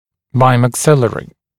[baɪmæk’sɪlərɪ][баймэк’силэри]двучелюстной; имеющий отношение одновременно к нижней и верхней челюсти